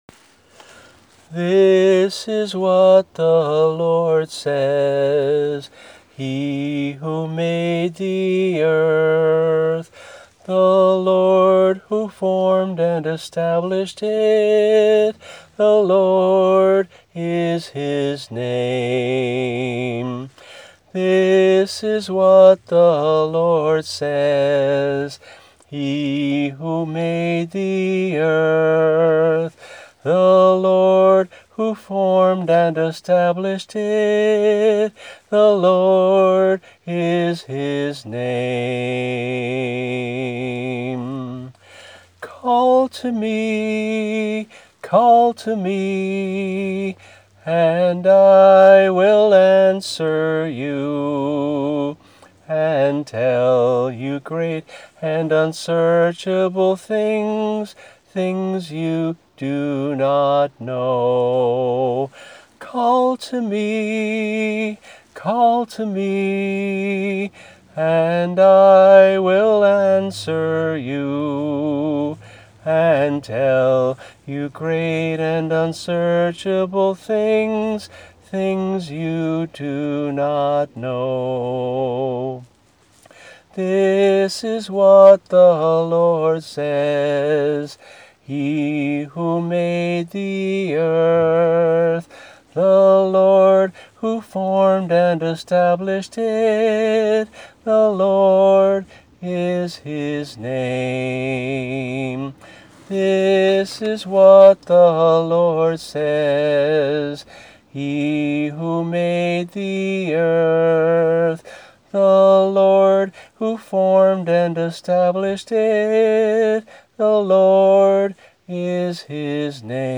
[MP3 - Voice only]